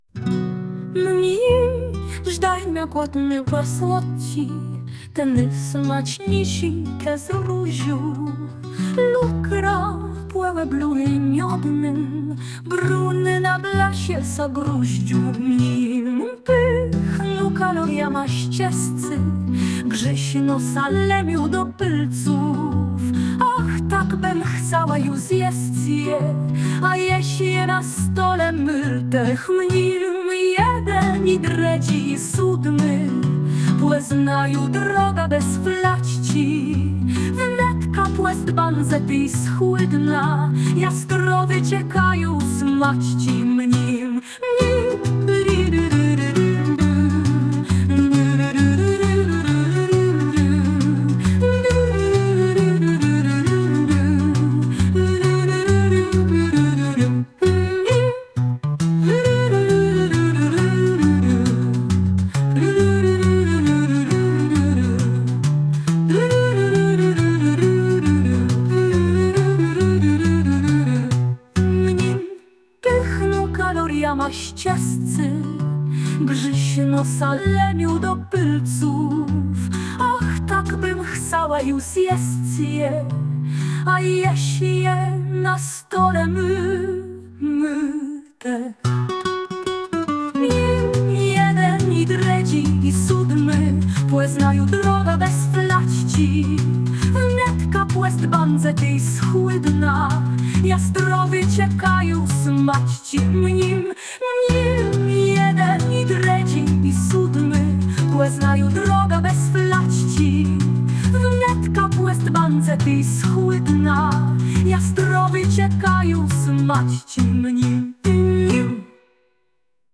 Podkład muzyczny tytuł